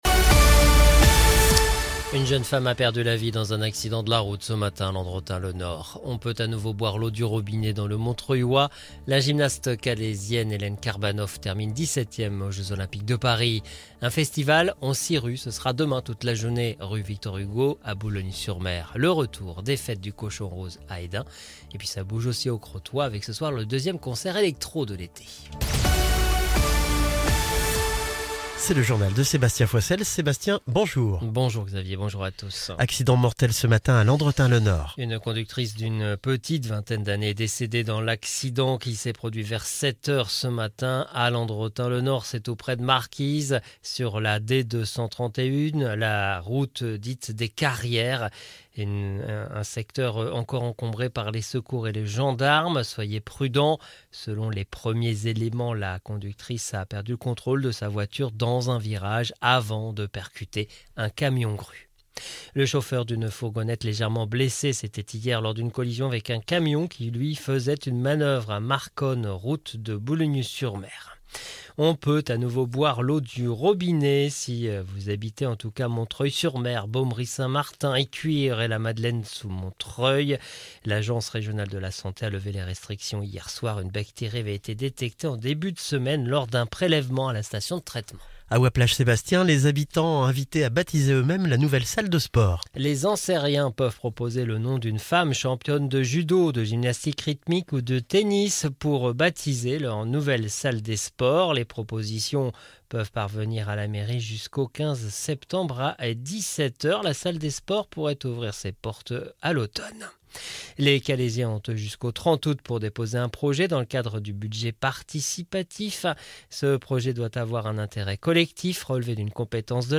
LE JOURNAL CÔTE D'OPALE ET CÔTE PICARDE DU vendredi 9 Août